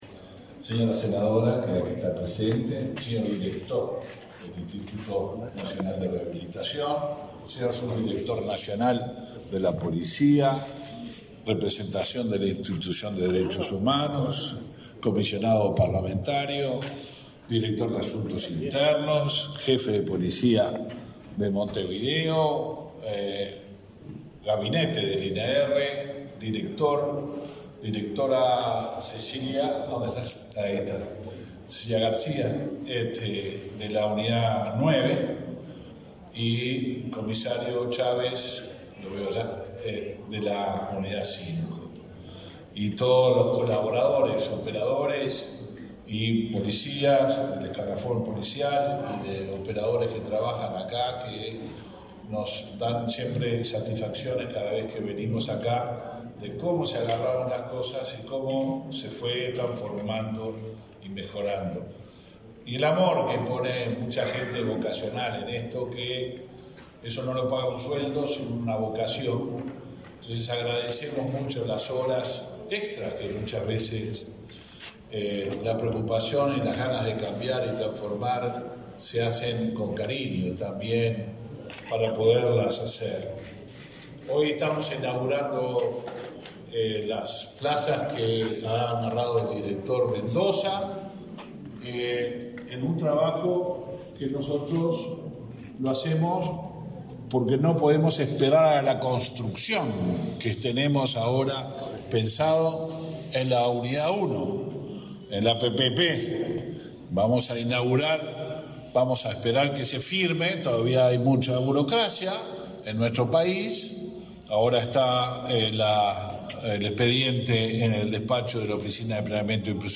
Palabras del ministro del Interior, Luis Alberto Heber
En el evento, el ministro Luis Alberto Heber realizó declaraciones.